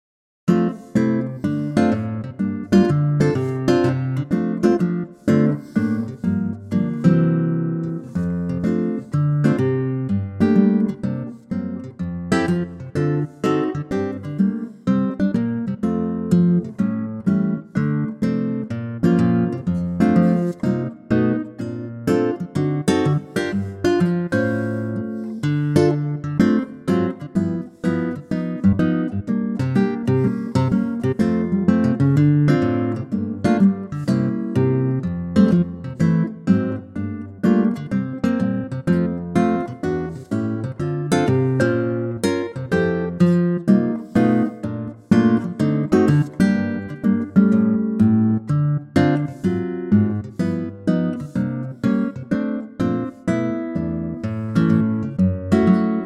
key - F - vocal range - C to E
A superb acoustic guitar arrangement
perfect for an intimate vocal or intimate instrumental.